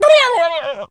turkey_ostrich_hurt_gobble_09.wav